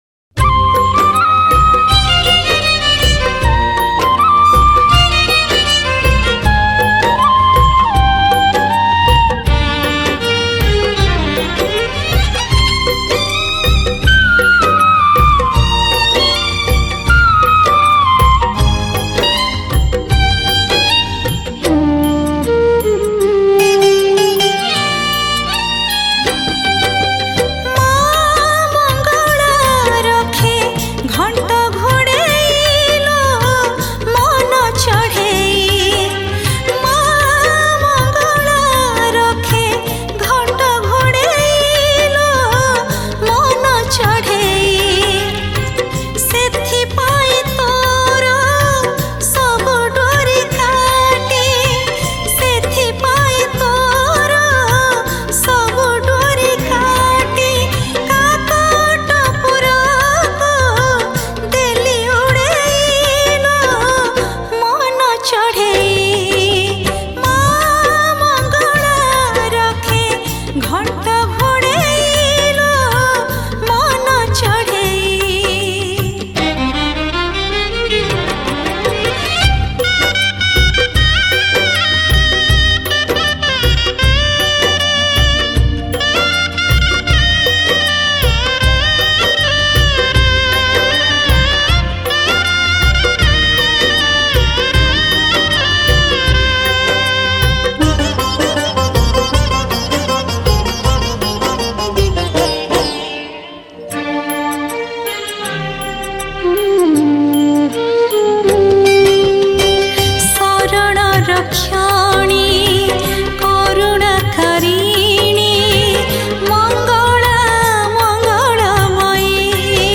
Category: Odia Bhakti Hits Songs